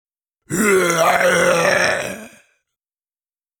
Sound Effects
Zombie Voice - Idle Moana 2